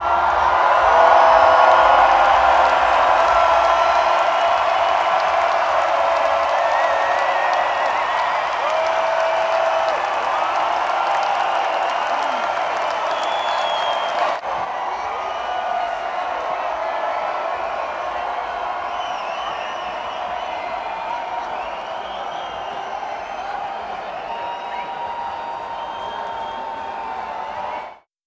cheer.wav